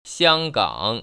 [Xiānggăng] 시앙깡  ▶